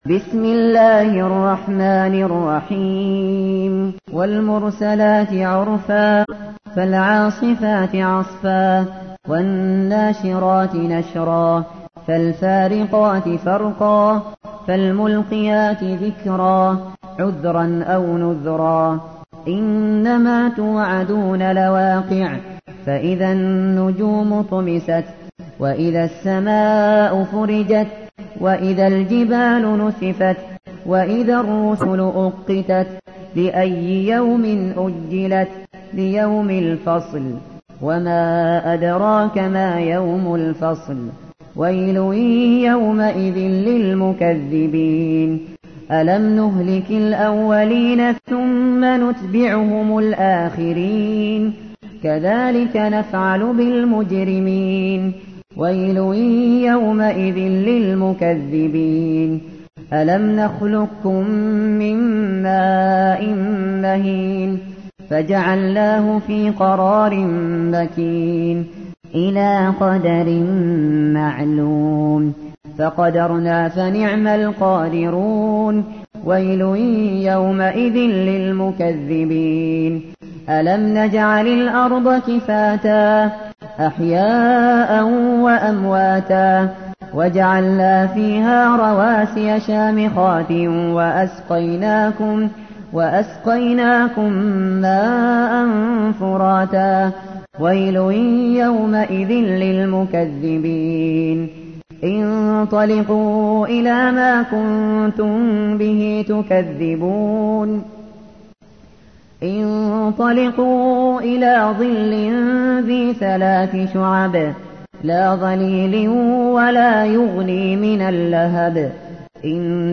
تحميل : 77. سورة المرسلات / القارئ الشاطري / القرآن الكريم / موقع يا حسين